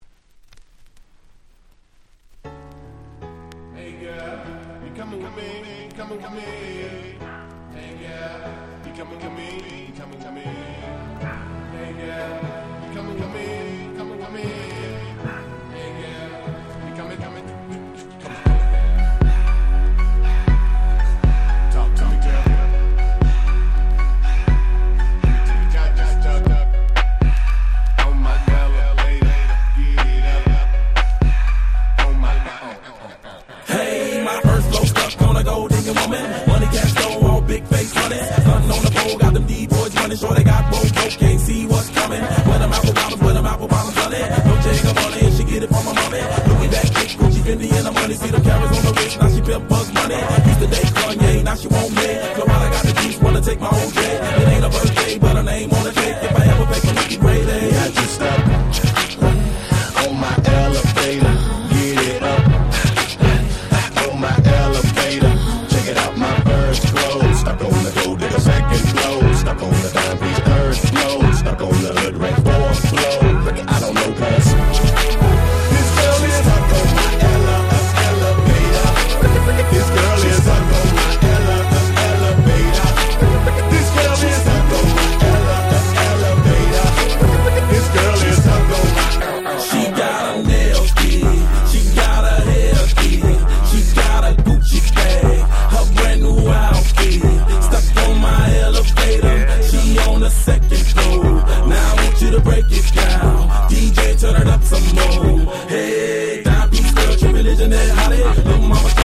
08' Super Hit Hip Hop !!